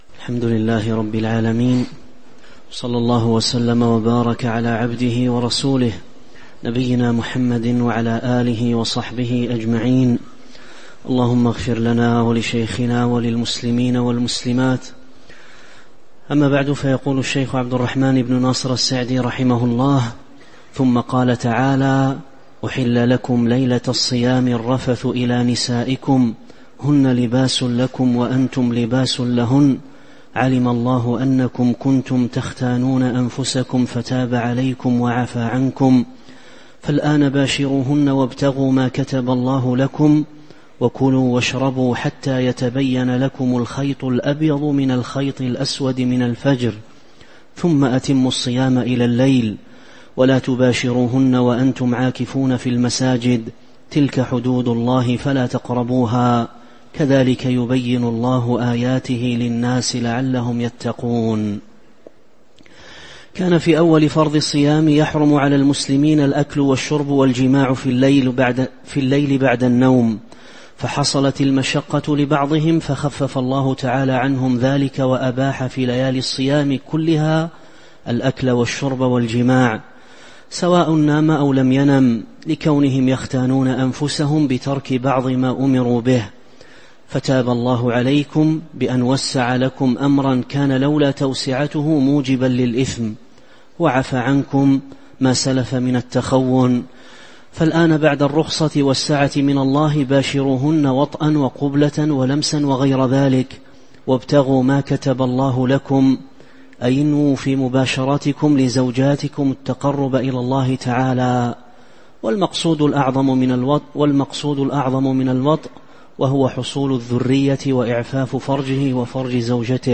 تاريخ النشر ١٦ رجب ١٤٤٦ هـ المكان: المسجد النبوي الشيخ